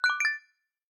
complete-quiet.aifc